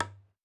Cue Strike Normal.wav